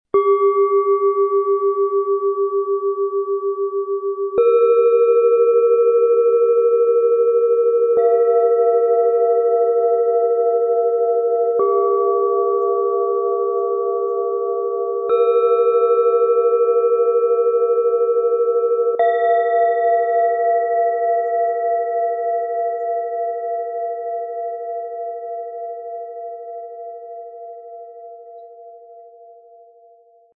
Erde dich, spüre tief und gestalte deinen Wandel in Stille - Set aus 3 Planetenschalen, Ø 9,7 -12,9 cm, 0,92 kg
Die drei Schalen wirken zentrierend, sanft und klärend. Trotz höherer Töne bringt der Tageston spürbare Erdung in Körper und Geist. Der höchste Ton reagiert fein auf den Anschlag – ideal für achtsames Lauschen.
Ihr Klang verändert sich je nach Anschlag – sanft angeschlagen klingt sie licht und leise, kräftiger gespielt offenbart sie neue Tiefe.
Im Sound-Player - Jetzt reinhören hören Sie den Originalton genau dieser Schalen. Besonders die Chiron-Schale zeigt, wie stark der Anschlag die Klangfarbe verändert – hören Sie selbst, wie fein Klang sich formen lässt.
Tiefster Ton: Tageston
Mittlerer Ton: Mond
Höchster Ton: Chiron
Wirkung: Transformierend, lichtvoll – der Klang variiert stark je Anschlag